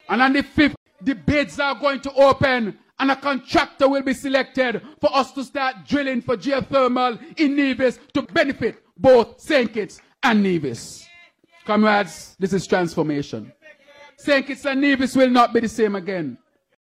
That was Prime Minister, Dr. Terrance Drew. He was speaking at a pre-Labour Day Meeting on Sunday April 27th, in St. Paul’s, St. Kitts.